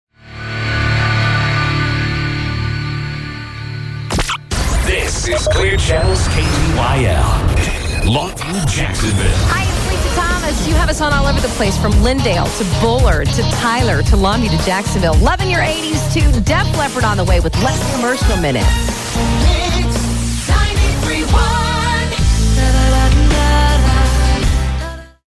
KTYL-FM Top of the Hour Audio: